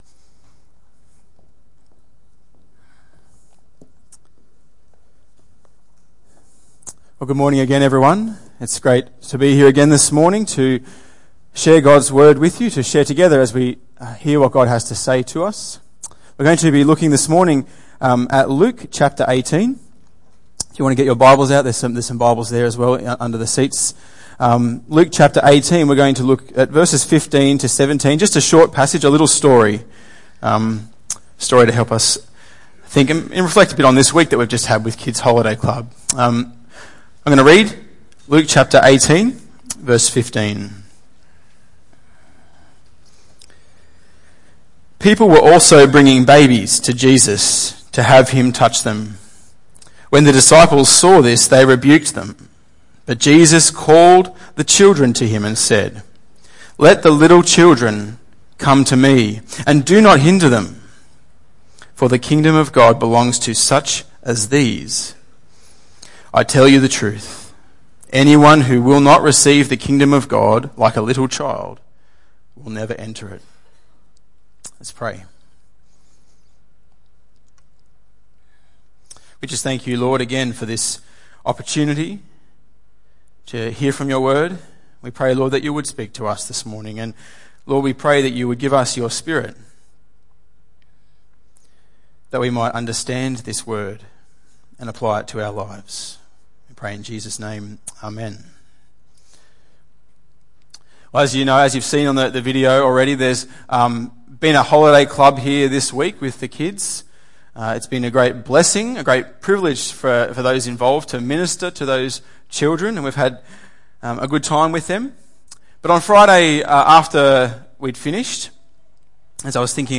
Luke 18:15-17 Tagged with Sunday Morning